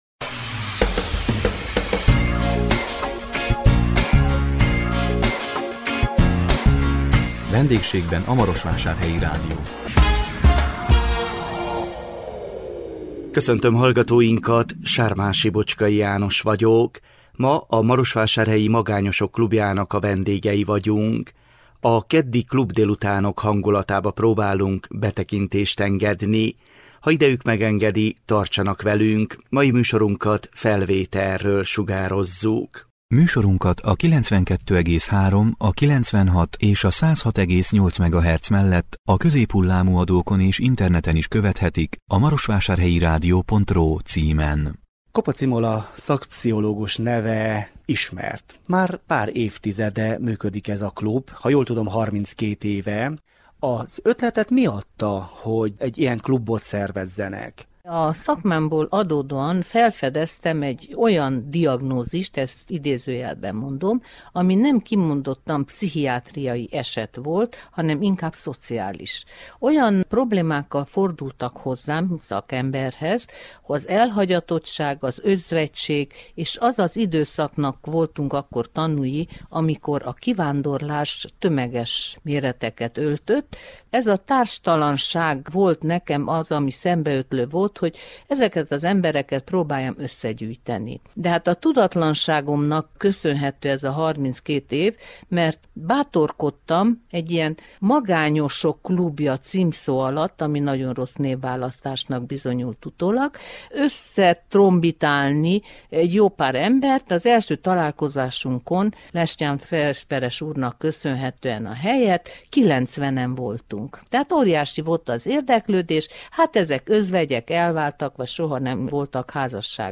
A 2023 augusztus 10-én a VENDÉGSÉGBEN A MAROSVÁSÁRHELYI RÁDIÓ című műsorunkkal a Marosvásárhelyi Magányosok Klubjának a vendégei voltunk. A keddi klubdélutánok hangulatába próbáltunk betekintést engedni.